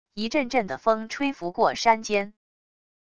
一阵阵的风吹拂过山间wav音频